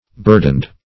burdened \bur"dened\ adj.